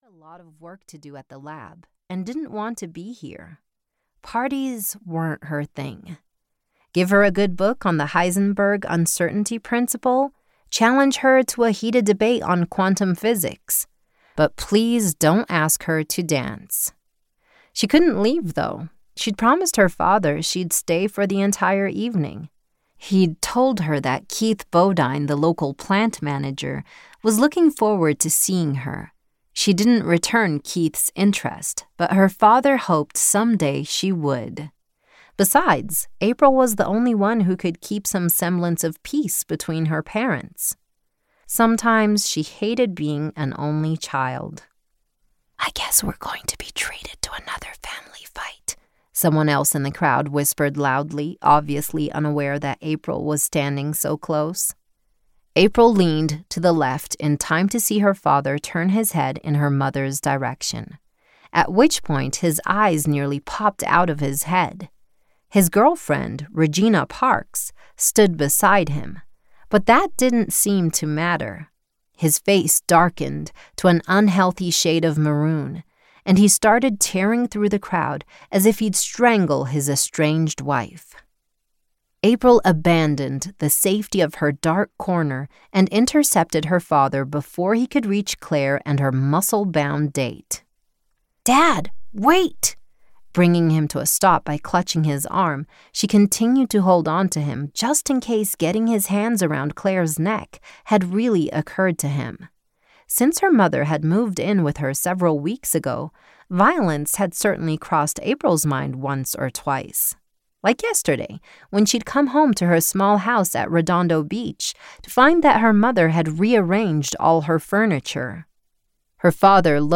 Audiobooks